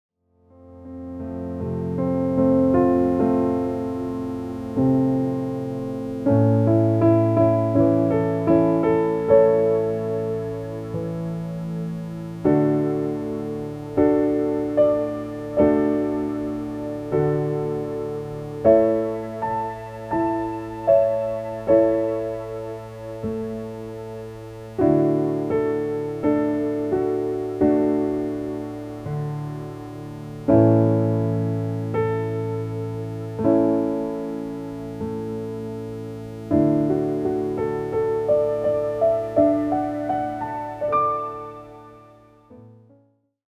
A) "Piano"